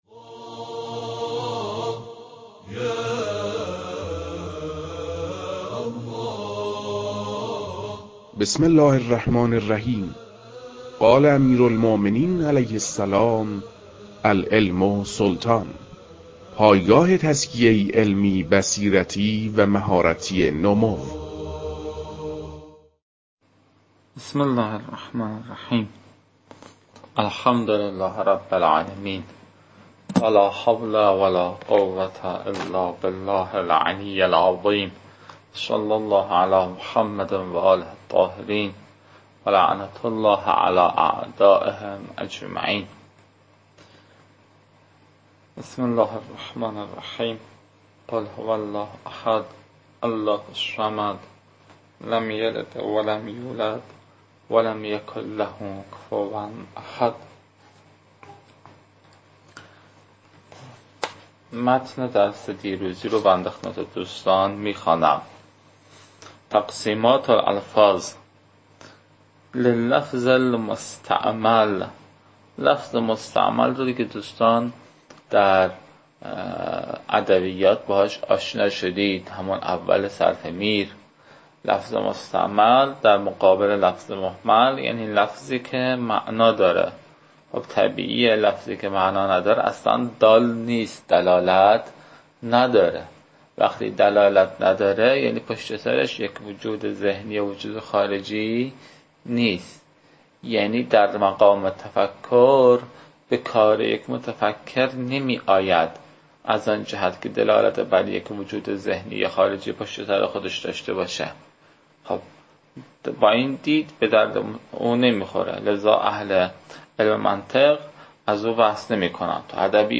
روخوانی تقسیمات الفاظ بما لفظ واحد